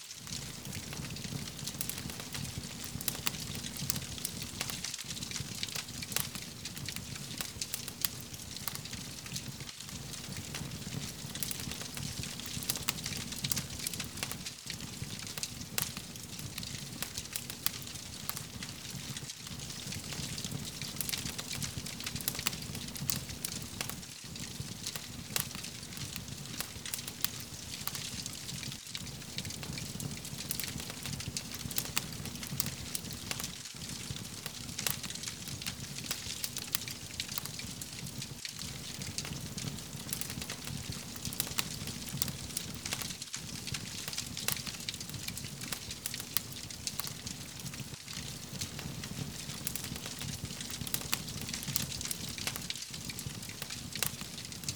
SND_fire_bonfire_small_Loop.ogg